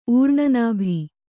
♪ ūrṇanābhi